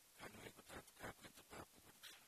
Pronunciation: ka:ni:wekuta:t ka:kwetəpa:pukutʃ
Pronunciation